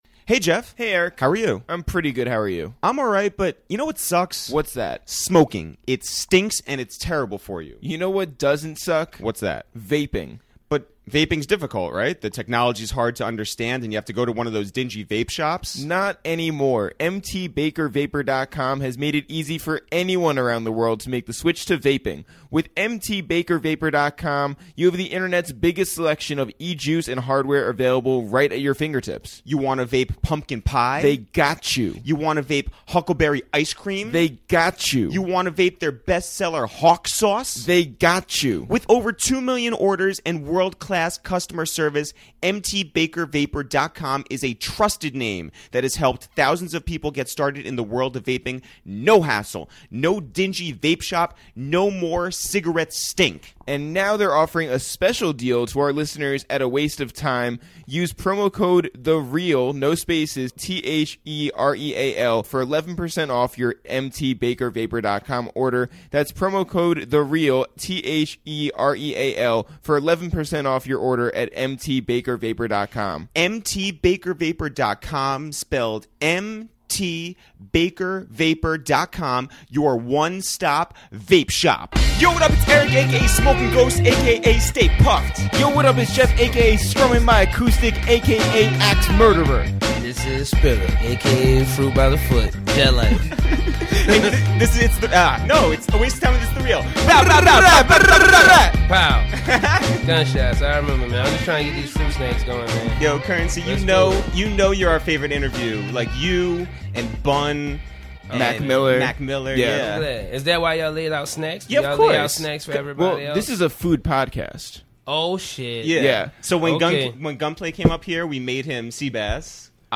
You know that whenever we get together with our friend Curren$y, it's the most hilarious conversation.